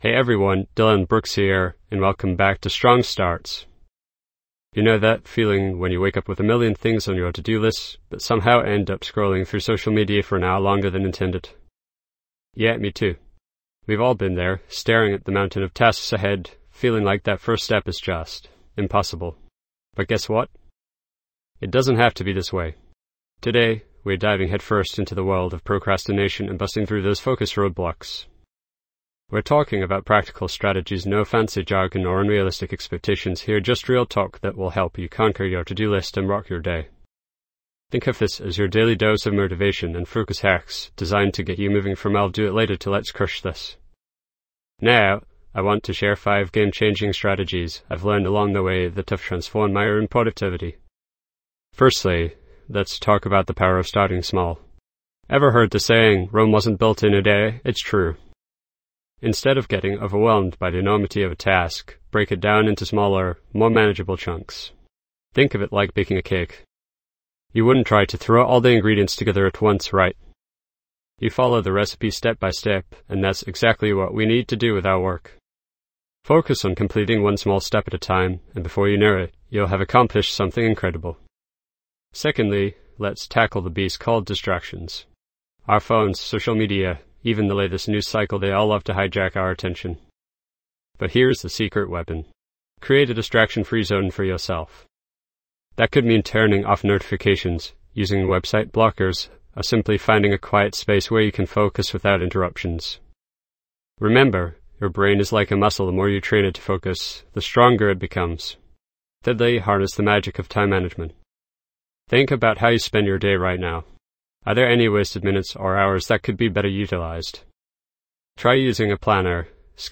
Podcast Category:. Education, Self-help, Personal Development, Productivity, Inspirational Talks nnSubscribe to Strong Starts:.
This podcast is created with the help of advanced AI to deliver thoughtful affirmations and positive messages just for you.